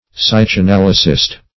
Meaning of psychanalysist. psychanalysist synonyms, pronunciation, spelling and more from Free Dictionary.
-- Psy*chan`a*lyt"ic , a. -- Psy`cha*nal"y*sist , n. [Webster 1913 Suppl.]